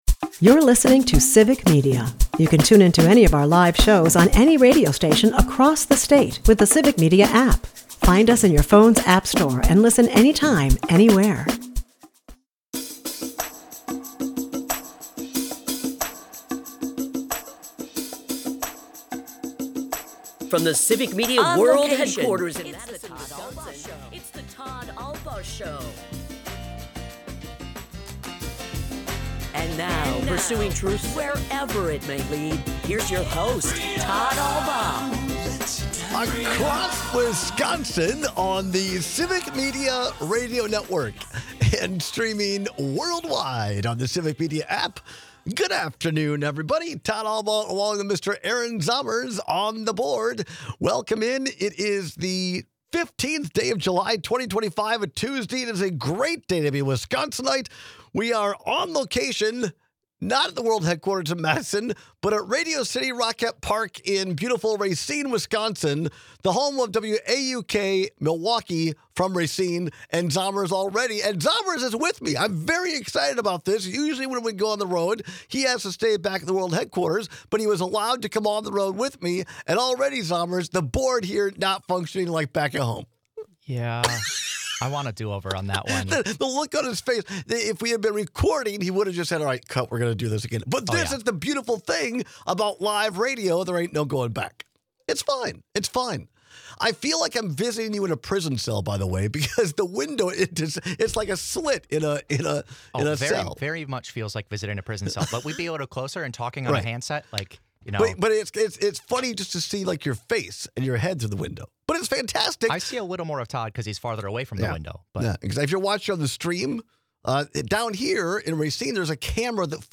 At the bottom of the hour, we are happy to welcome Representative Tip McGuire, a Democrat who represents Racine.